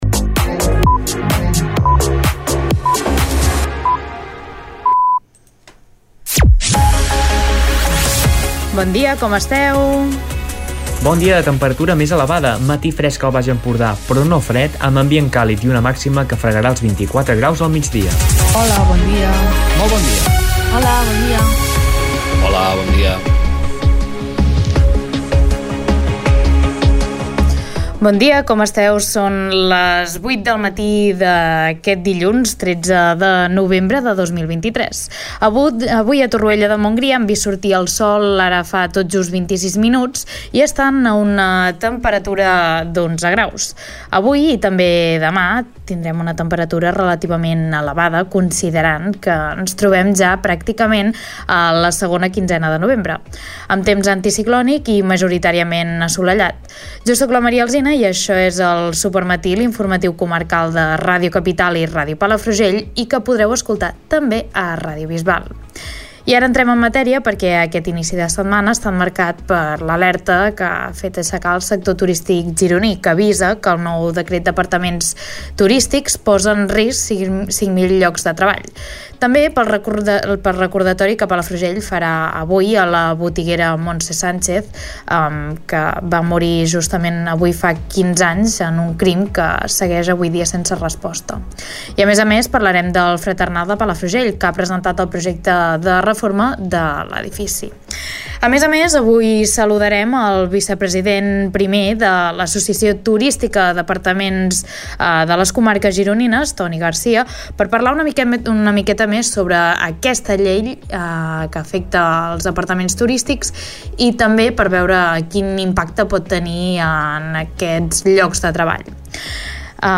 Escolta l'informatiu d'aquest dilluns